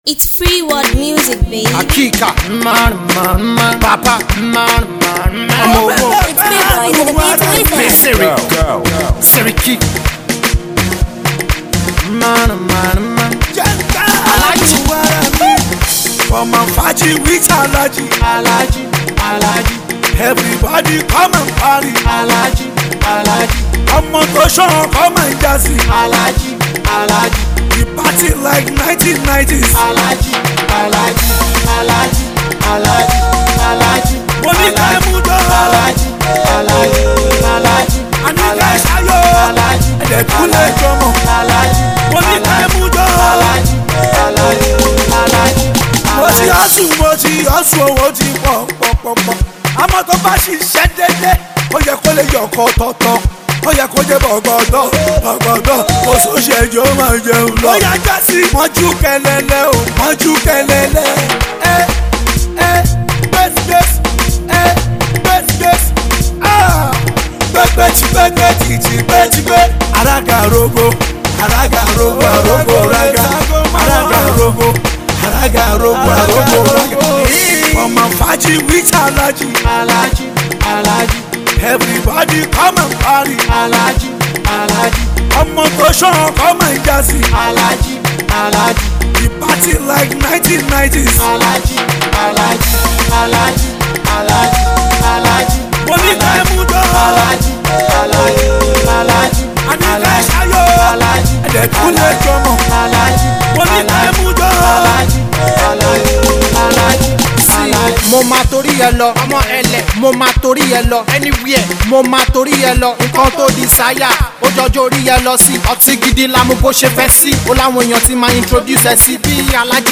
featuring veteran indigenous rapper